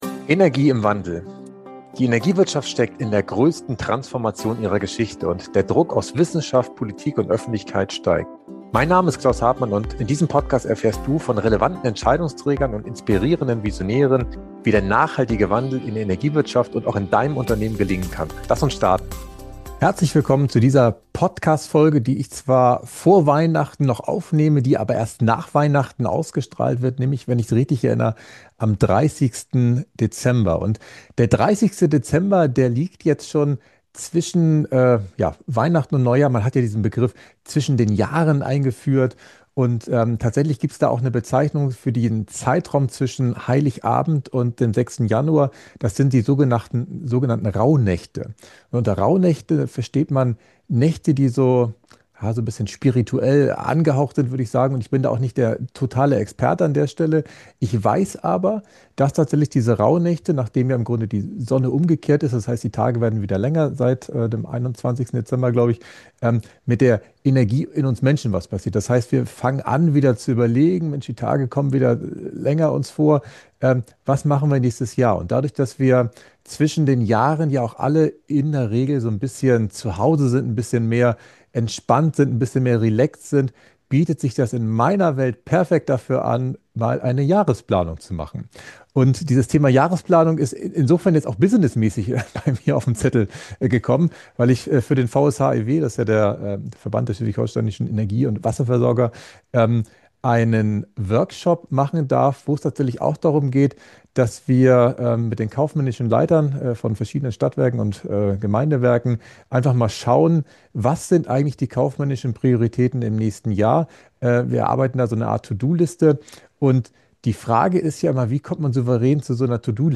In dieser Solofolge nehme ich dich mit in meine persönliche Art der Jahresplanung, die sich seit Jahren bewährt hat – beruflich wie privat. Ich spreche über die Rauhnächte als Zeit der Ausrichtung, über Visionboards als kraftvolles Werkzeug für dein Unterbewusstsein und darüber, wie aus Bildern echte Umsetzung entsteht.